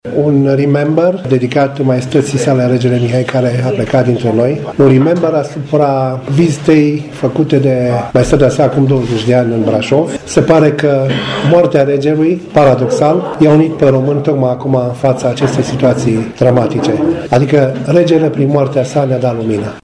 La galeriile brașovene KronArt a avut loc o impresionantă evocare a personalității Regelui Mihai I al României.